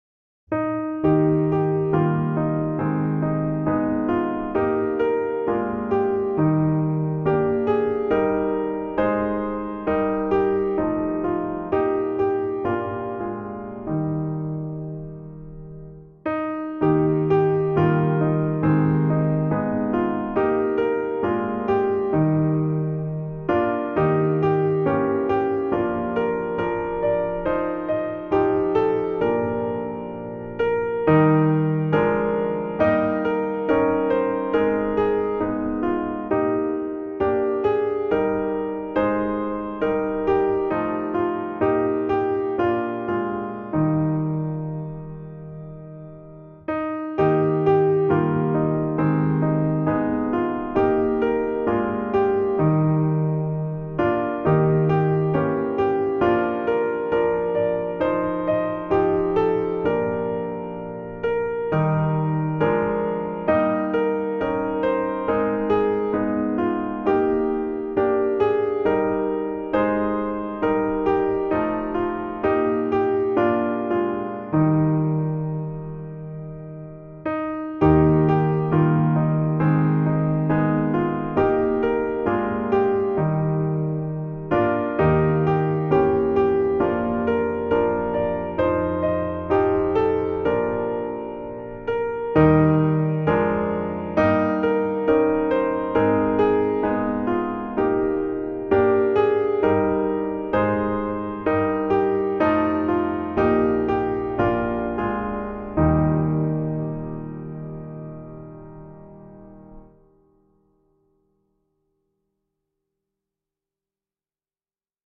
Voicing/Instrumentation: Primary Children/Primary Solo
Children's Songs